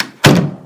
door-close.mp3